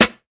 • '00s Juicy Rap Snare Drum Sample A# Key 184.wav
Royality free snare drum sound tuned to the A# note. Loudest frequency: 2760Hz
00s-juicy-rap-snare-drum-sample-a-sharp-key-184-wXZ.wav